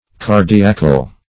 Cardiacle \Car"di*a*cle\, n. A pain about the heart.